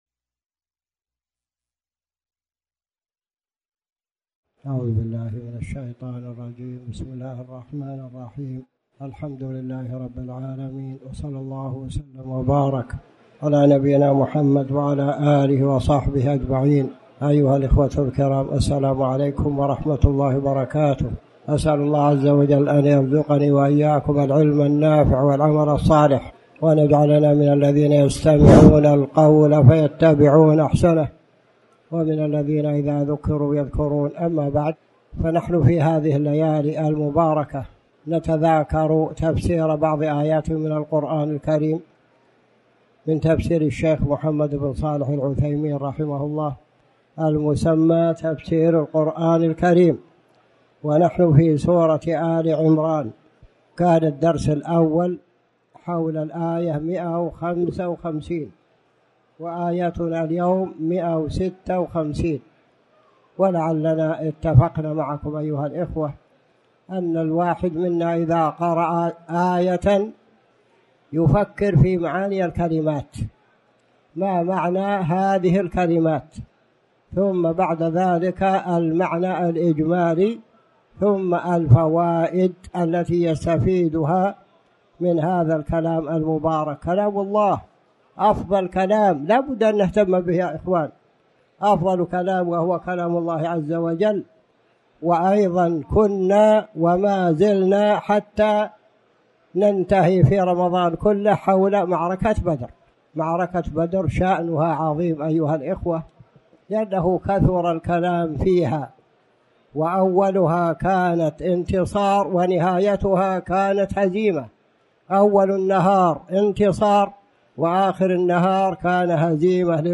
تاريخ النشر ٢٤ رمضان ١٤٣٩ هـ المكان: المسجد الحرام الشيخ